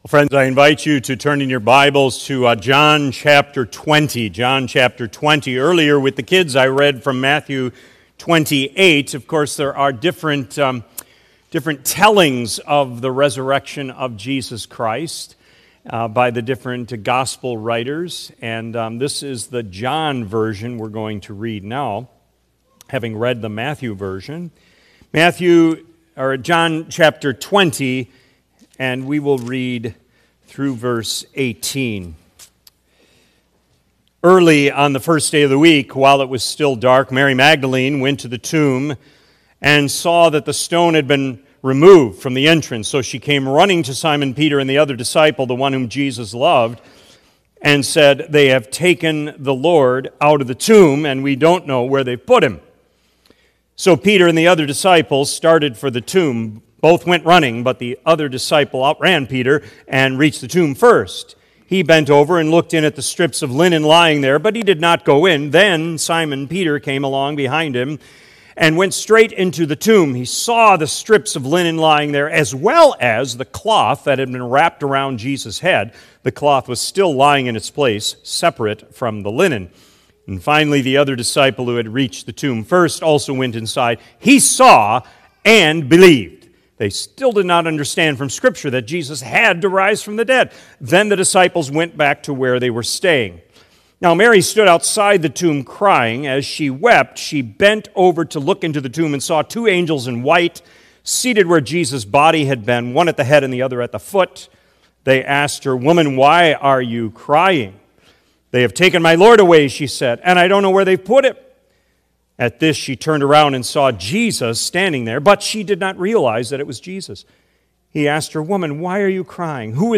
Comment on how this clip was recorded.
“The Voice of Service” April 20 2025, A.M. Service. Easter Morning